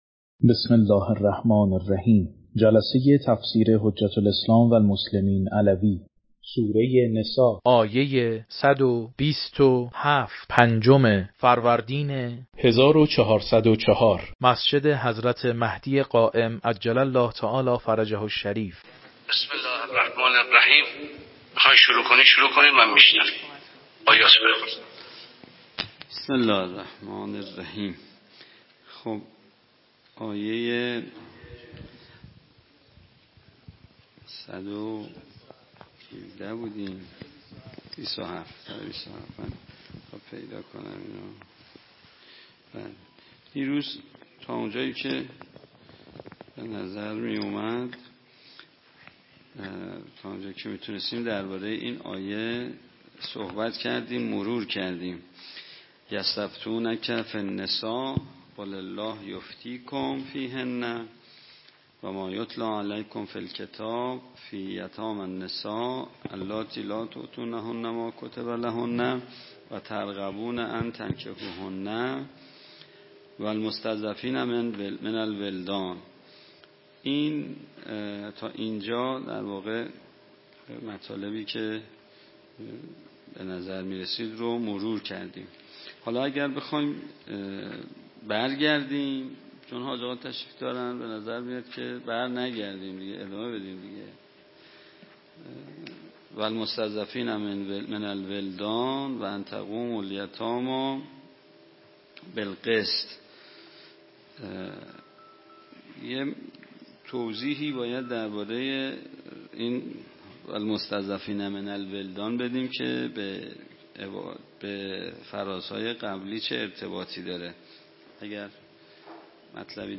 تفسیر قرآن
مسجد حضرت قائم (عج)